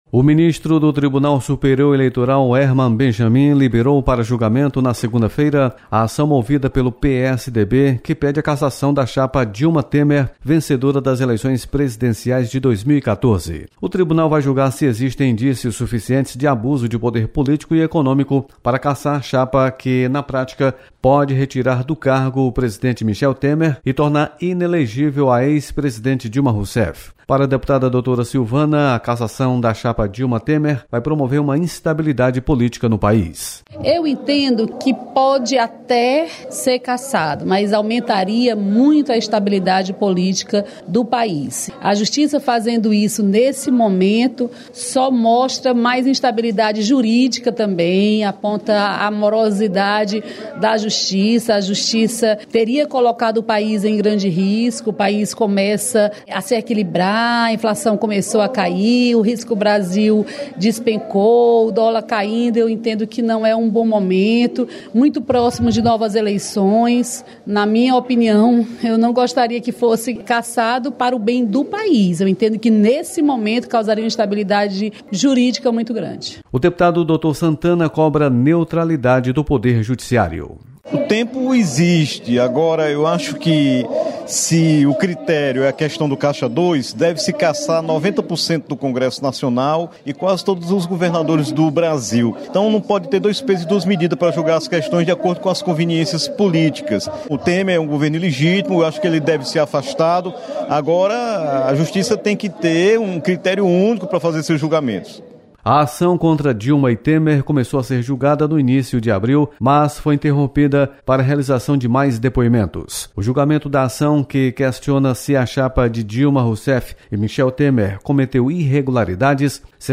TSE  retoma julgamento contra a chapa Dilma-Temer em junho. Repórter